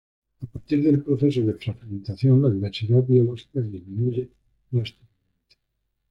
pro‧ce‧so
Pronunciado como (IPA)
/pɾoˈθeso/